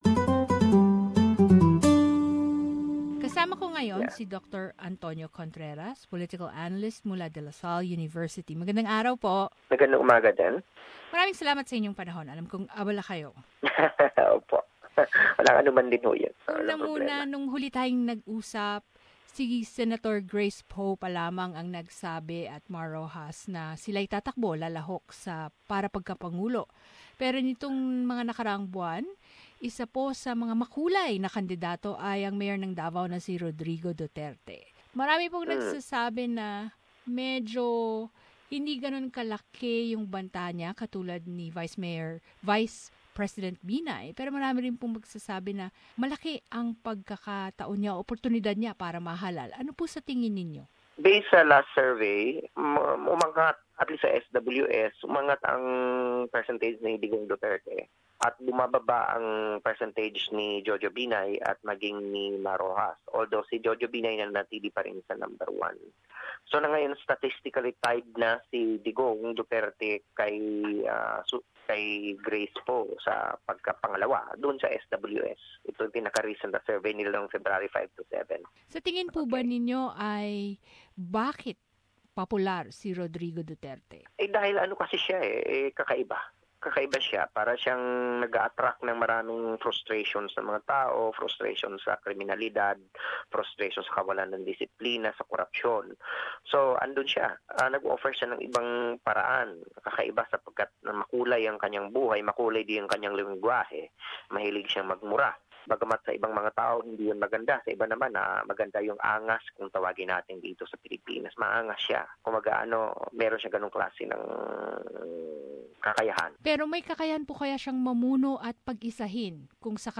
While candidates for May 2016 elections are busy wooing voter support, survey shows that former President Ferdinand Marcos son Senator Bong Bong Marcos has made a big leap in the polls. We ask political analyst